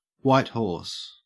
Ääntäminen
Ääntäminen AU